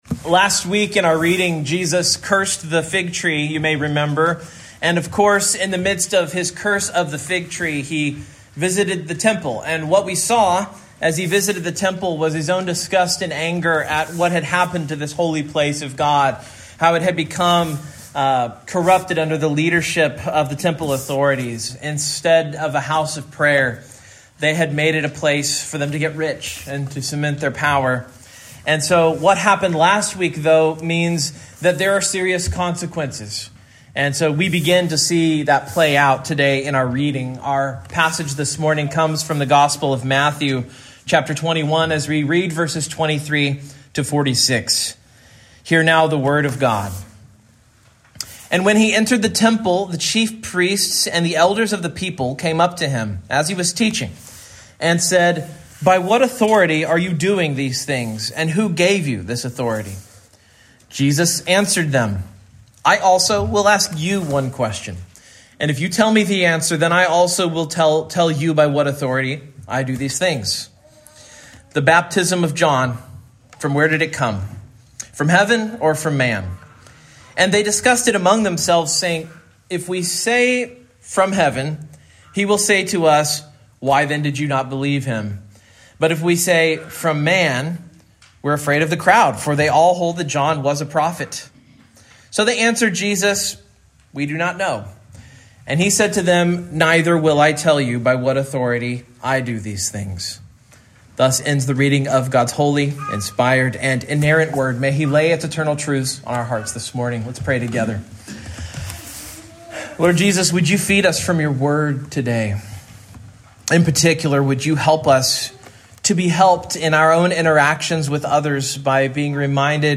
Matthew 21:23-27 Service Type: Morning Main Point